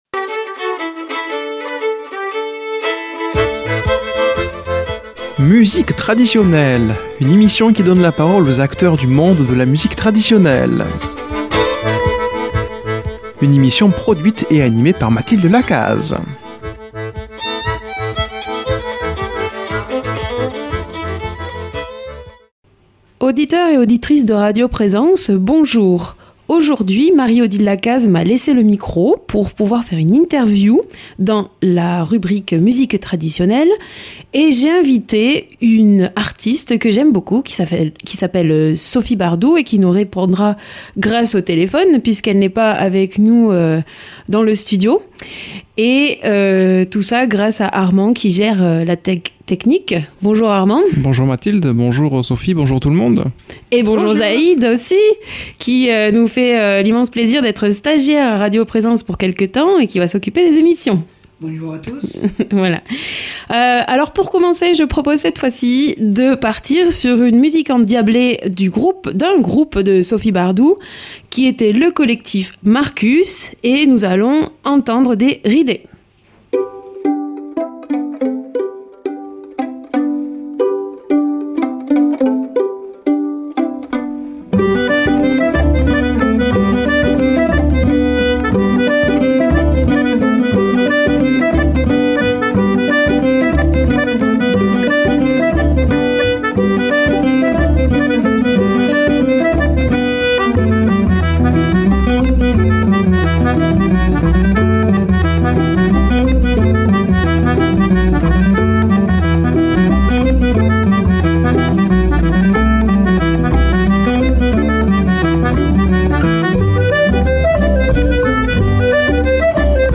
mardi 5 mars 2024 Musique Traditionnelle Durée 28 min
Animatrice